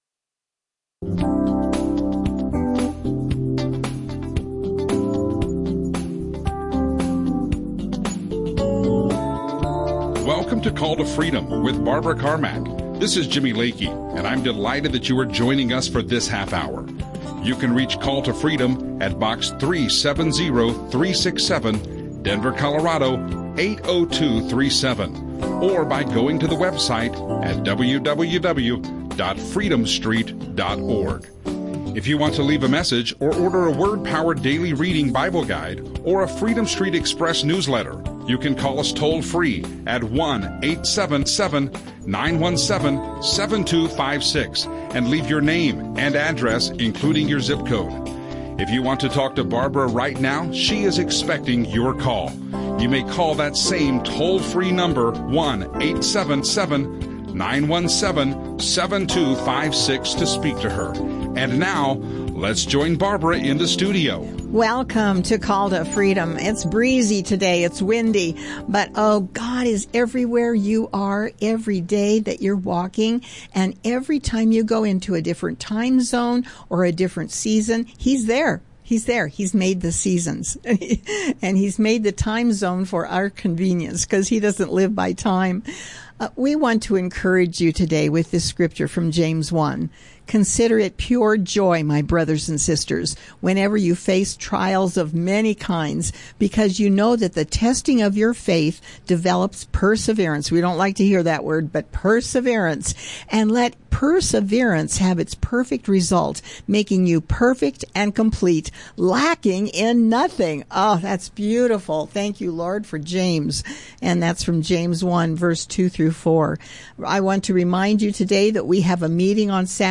Audio teachings